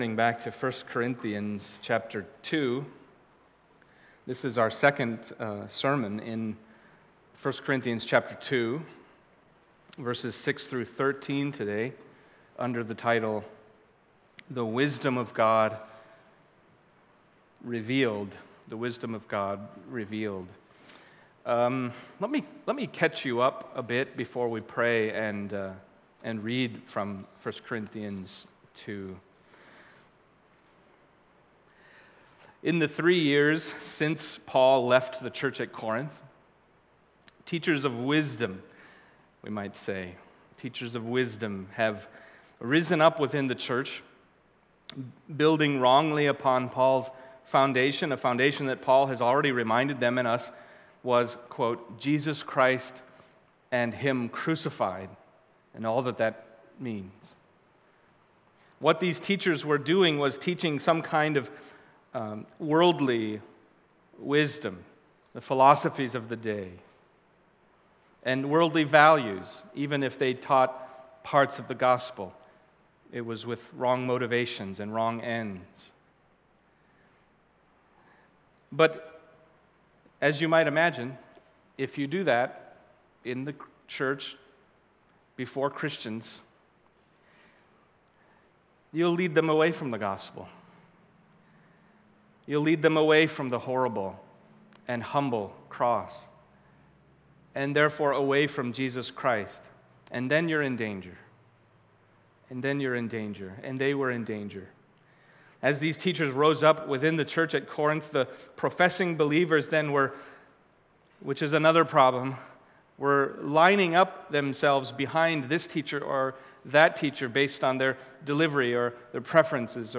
Passage: 1 Corinthians 2:6-13 Service Type: Sunday Morning